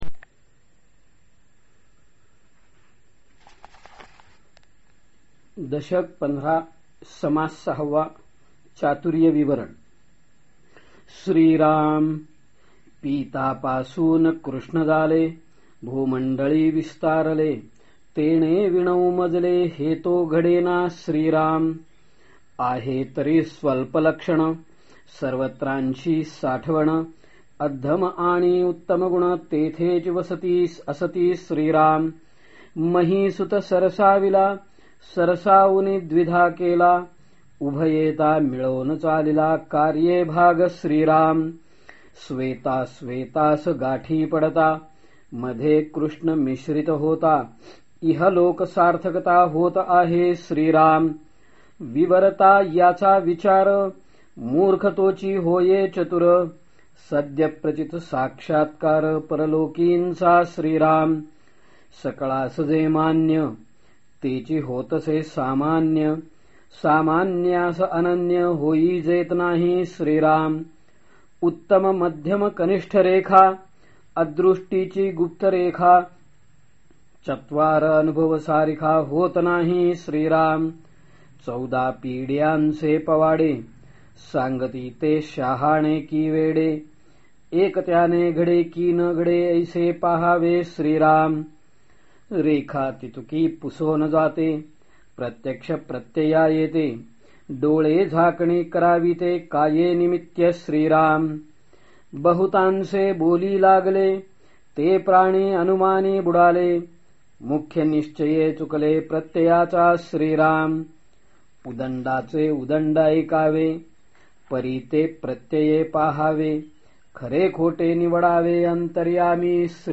श्रीमत् दासबोध वाचन समास १५.६ ते १५.१० # Shreemat Dasbodh Vachan Samas 15.6 to 15.10